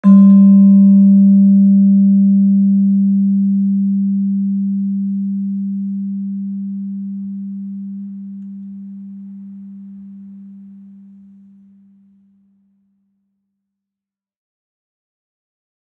Gender-2-G2-f.wav